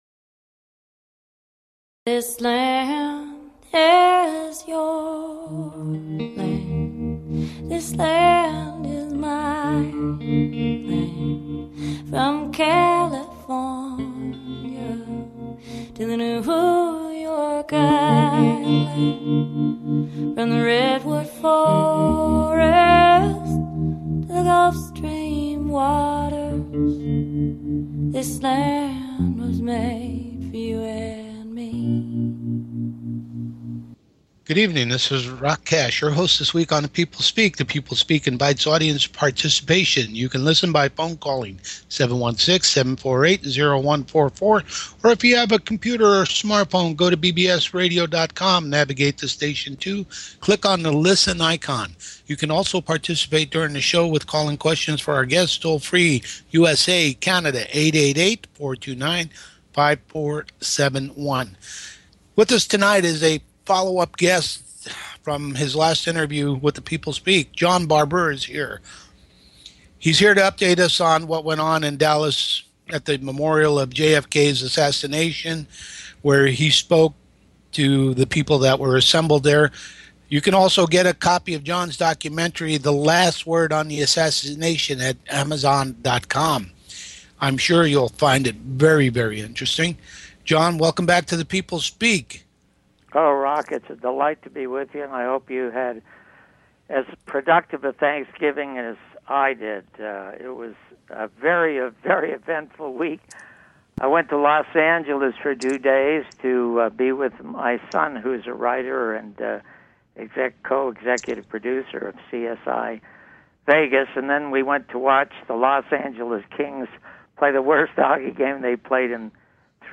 Guest, John Barbour